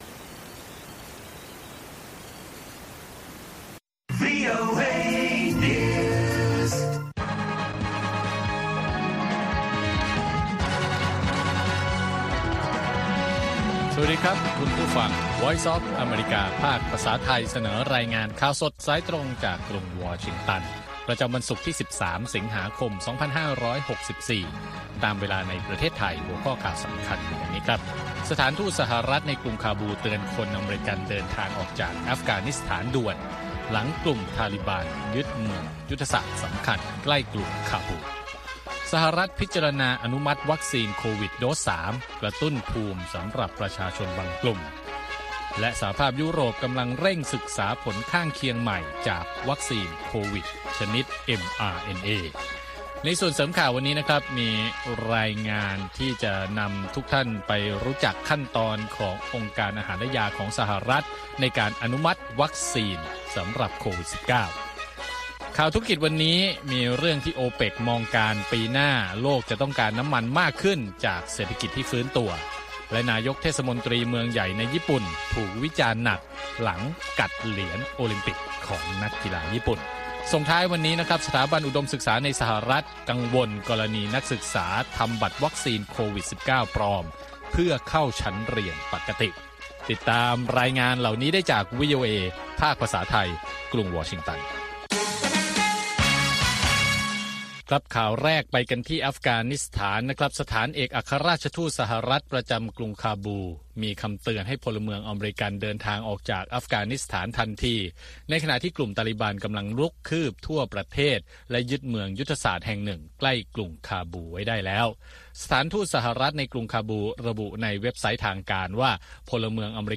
ข่าวสดสายตรงจากวีโอเอ ภาคภาษาไทย 8:30–9:00 น. ประจำวันศุกร์ที่ 13 สิงหาคม ตามเวลาในประเทศไทย